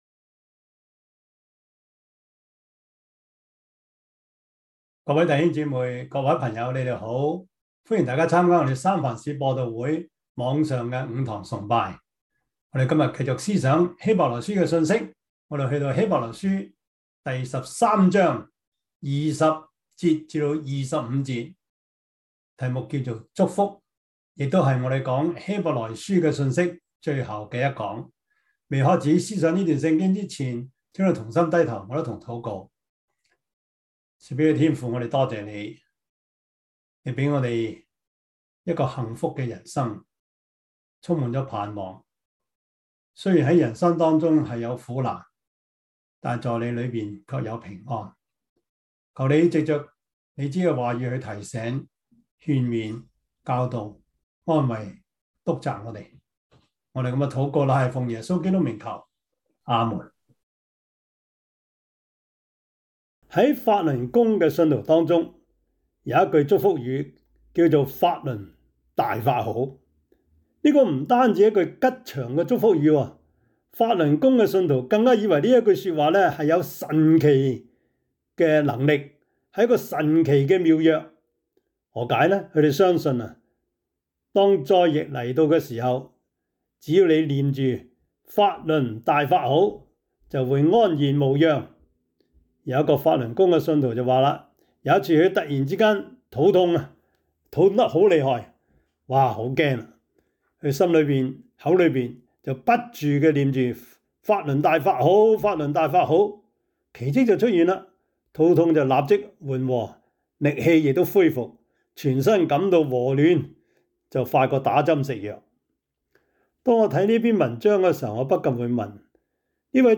Service Type: 主日崇拜
Topics: 主日證道 « 那等候耶和華的必重新得力 不要害怕 »